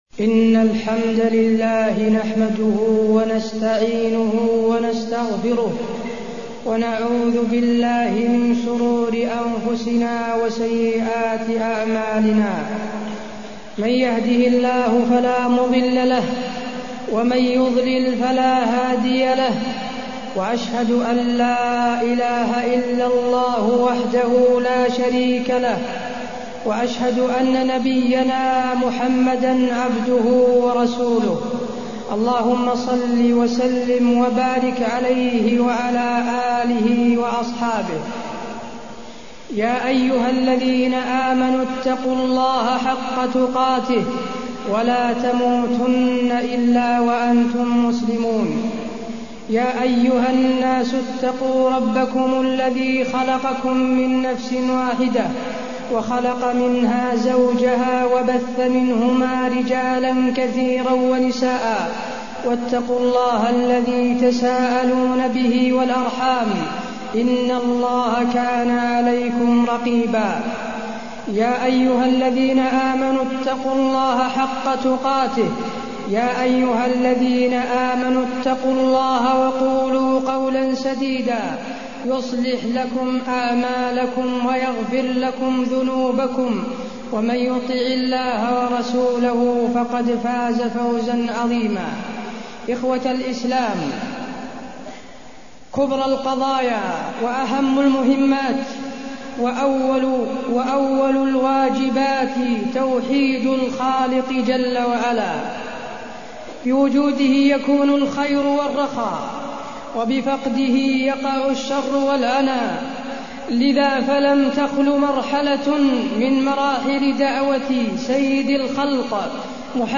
تاريخ النشر ٢٩ ذو القعدة ١٤٢١ هـ المكان: المسجد النبوي الشيخ: فضيلة الشيخ د. حسين بن عبدالعزيز آل الشيخ فضيلة الشيخ د. حسين بن عبدالعزيز آل الشيخ الحج وعشر ذي الحجة